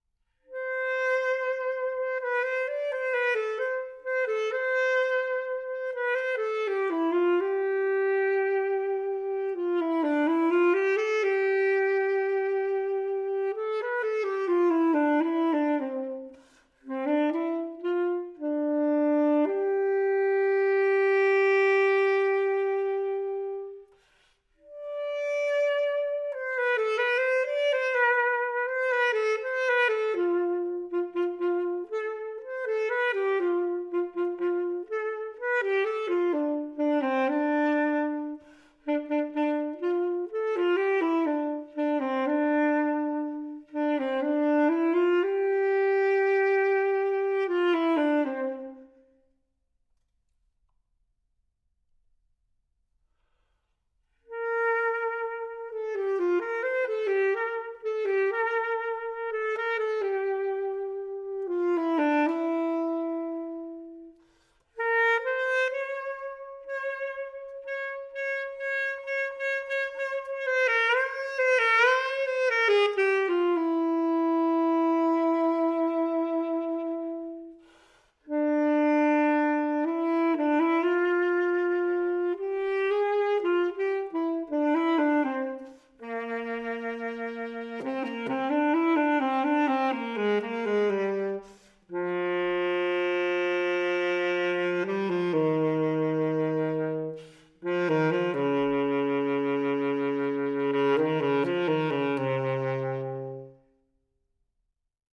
Tenorsaxophon, Romeo Orsi S.r.l., Buccinasco (Mailand), 1991.
tenorsaxophon.mp3